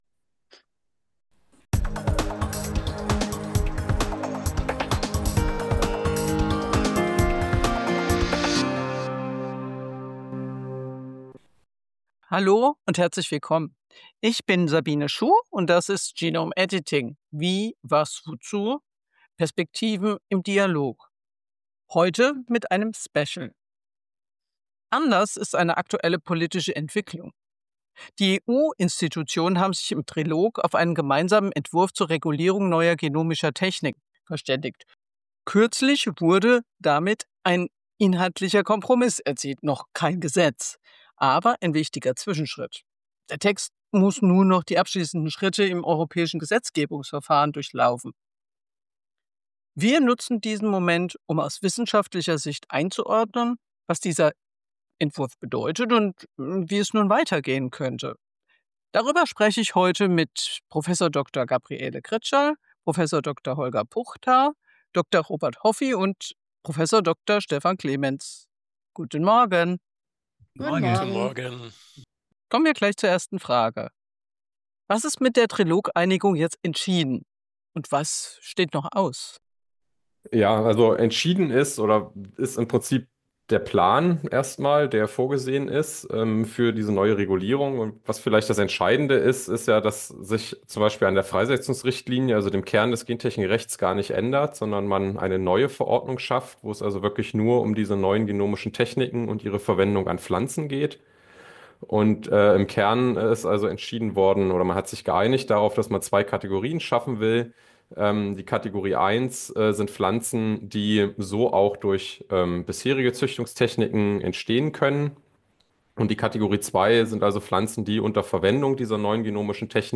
Und welche Folgen zeichnen sich für Forschung und Pflanzenzüchtung in Europa ab? Im Gespräch diskutieren Expertinnen und Experten aus der Pflanzenforschung über Risikoabschätzung, praktische Auswirkungen der geplanten Regelung, die Rolle von Patenten sowie realistische Erwartungen an das weitere Gesetzgebungsverfahren.